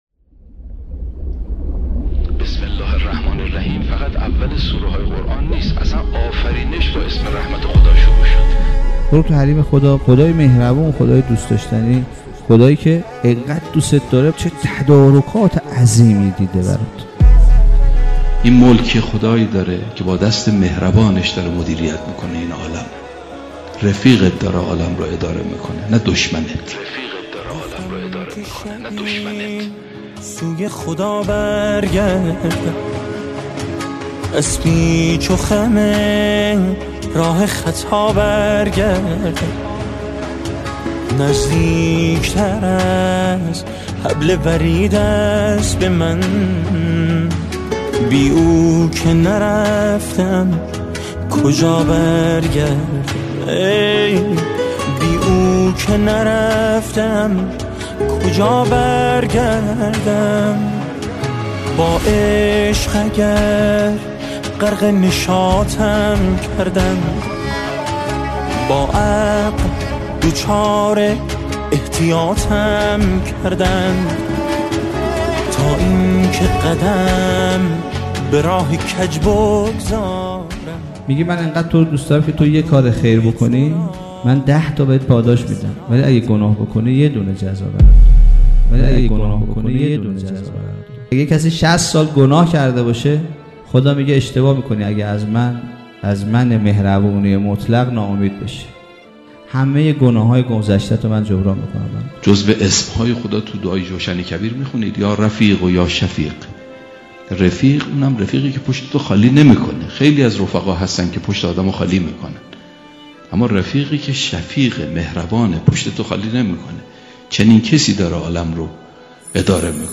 بخشی از سخنرانی